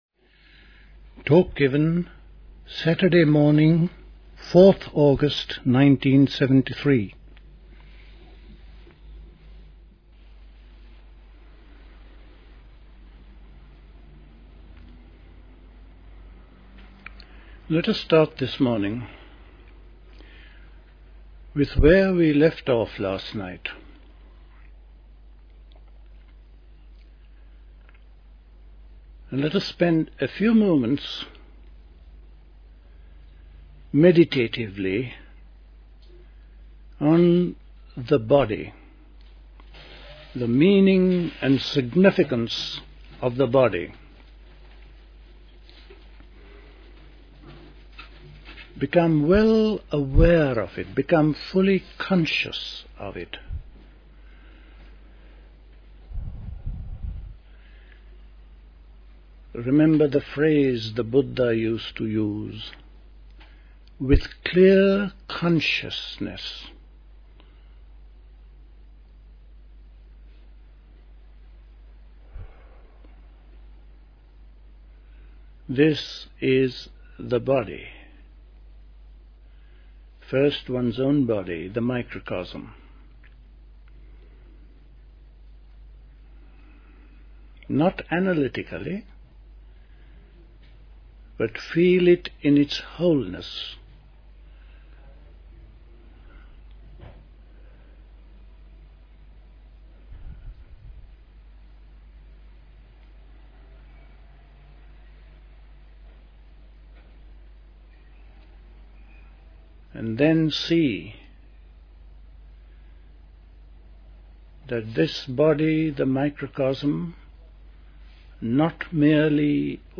Recorded at the 1973 Dilkusha Summer School. Includes discussion at the end of the talk.